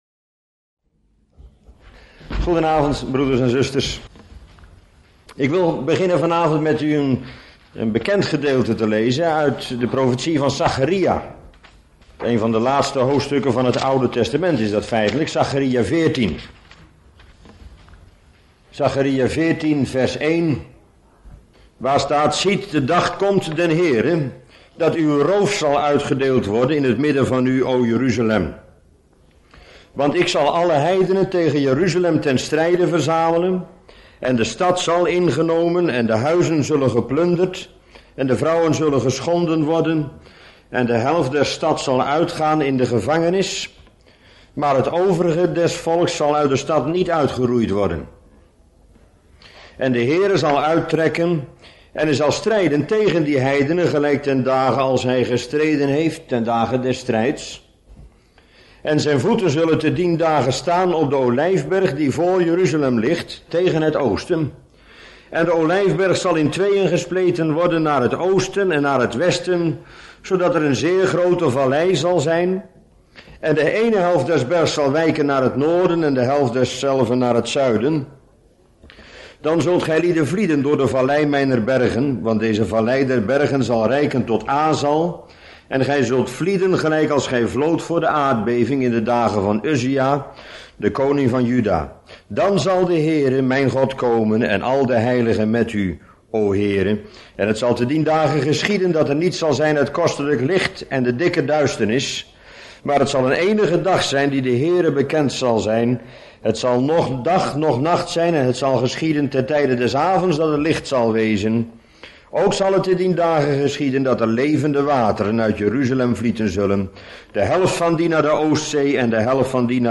Bijbelstudie lezing onderwerp: Israëls bekering (Zach.14)